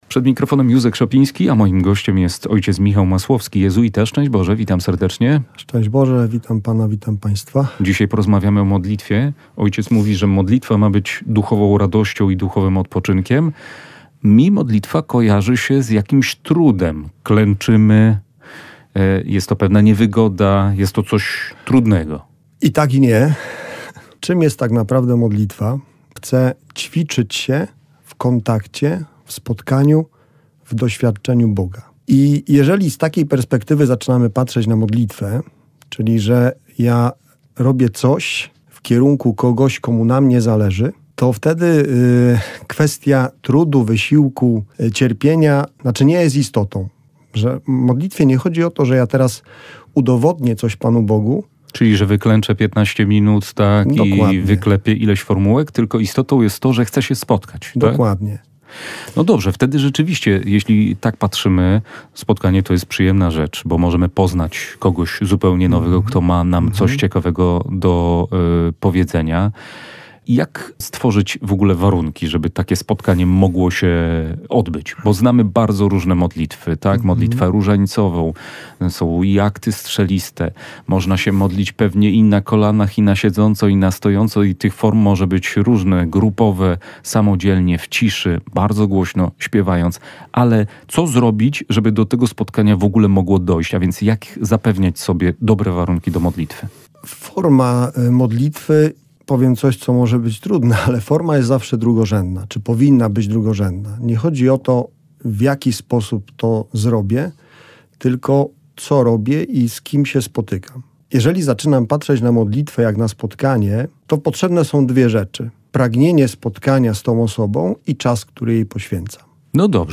Rozmowa dla tych, którzy się nie modlą i dla tych, którzy szukają nowych inspiracji do tego jak się modlić.